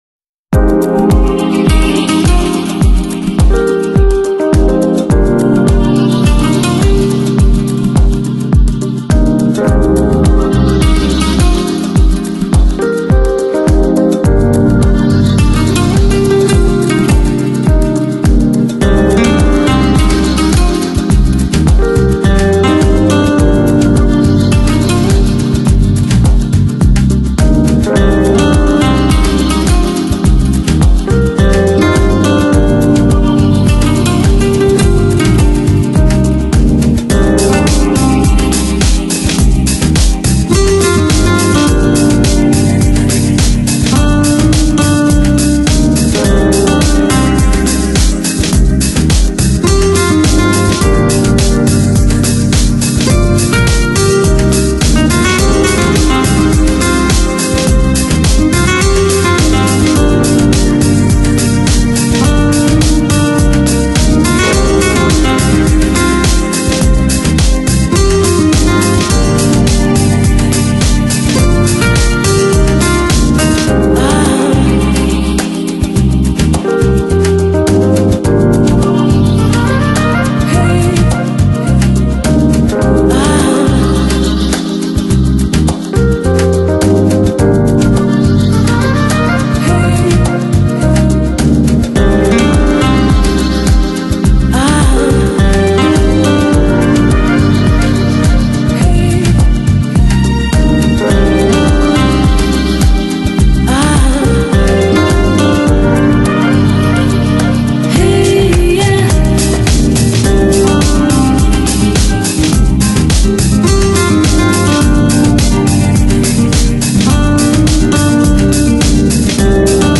Genre: Downtempo, Chillout, Lounge